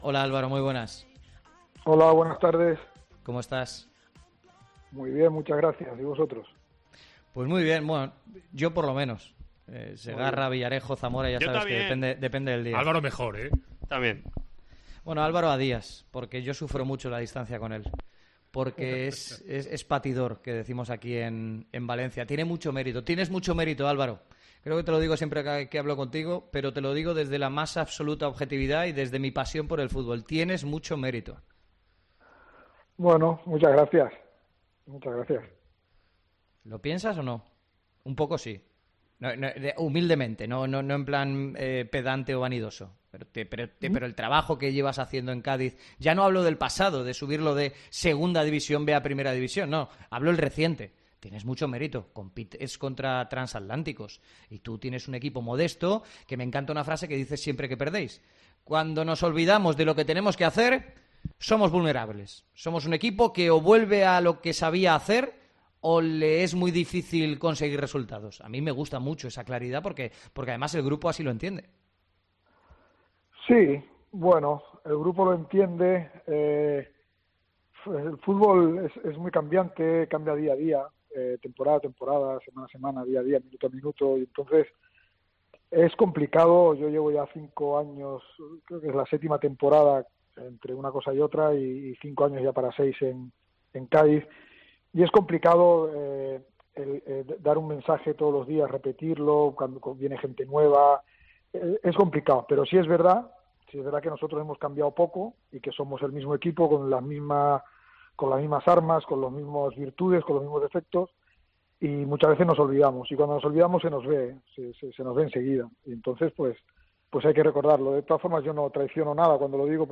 AUDIO. Entrevista a Álvaro Cervera en Deportes COPE Valencia